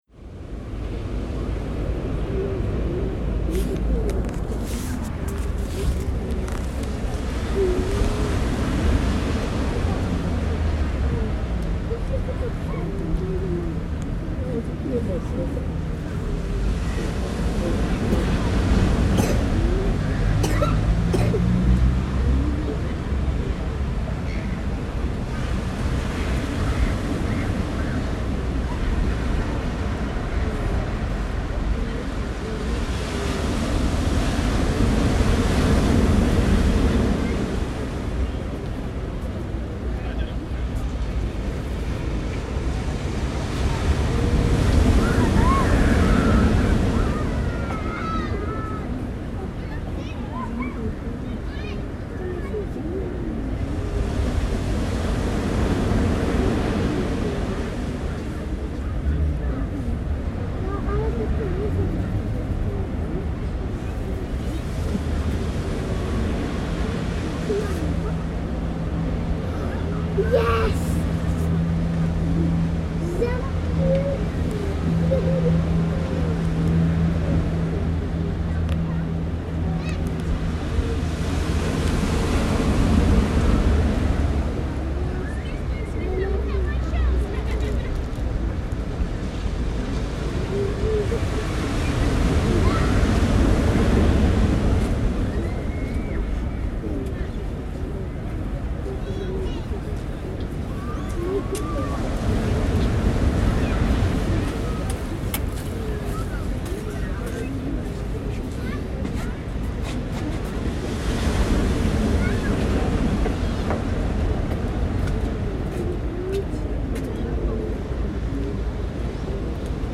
Surf breaking on Coogee Beach, Sydney, Australia.